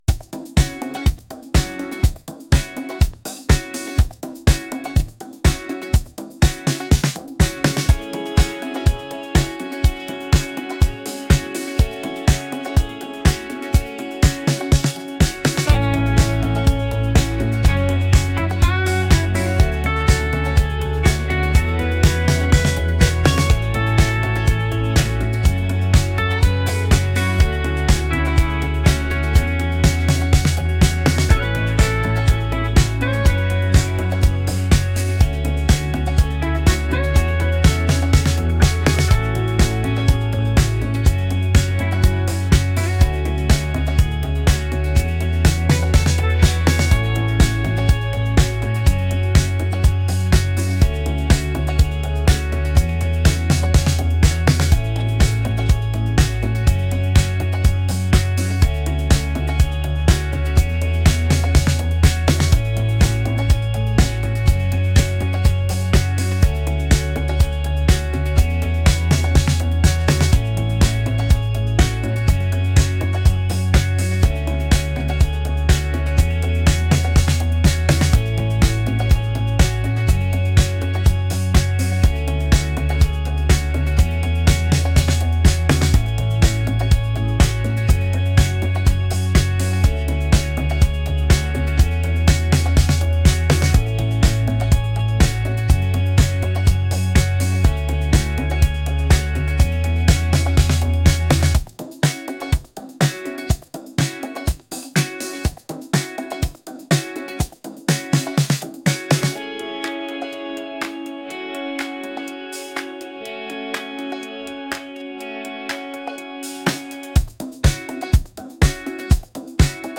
upbeat | groovy